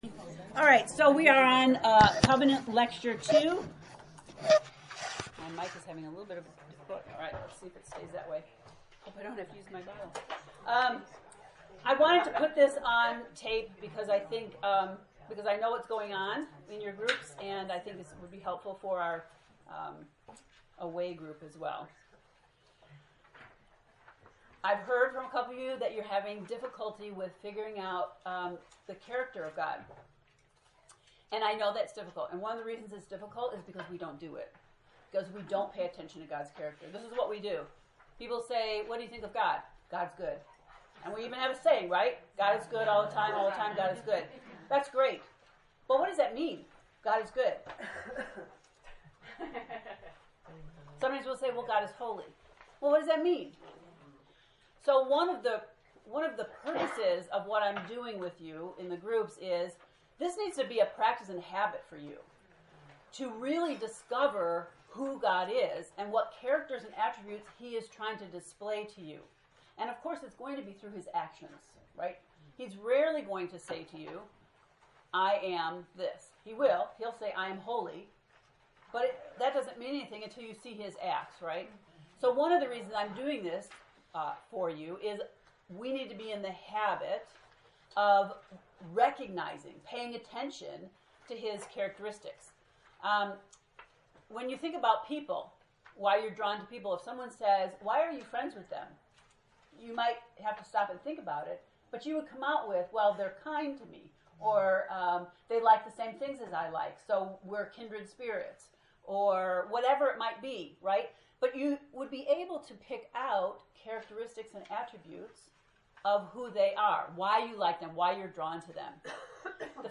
COVENANT lecture 2
covenant-lect-2.mp3